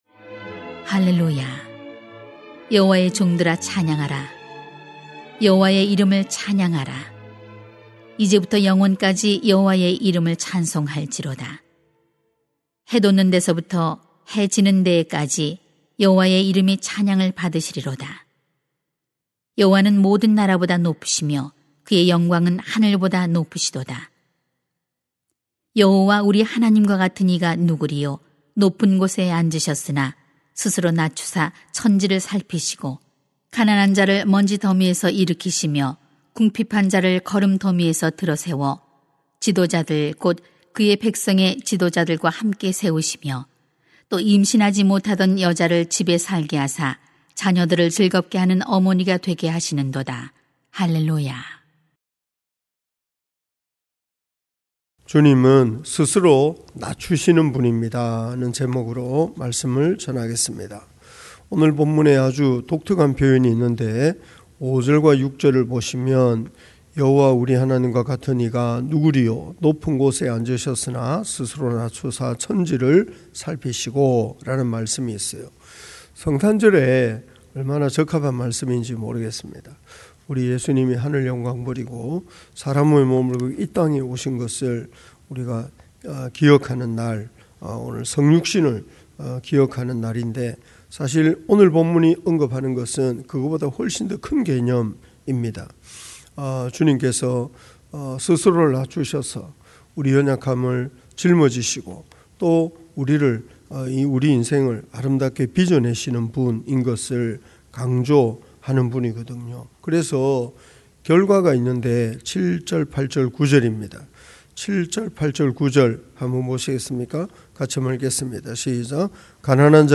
[시 113:1-9] 주님은 스스로 낮추시는 분입니다 > 새벽기도회 | 전주제자교회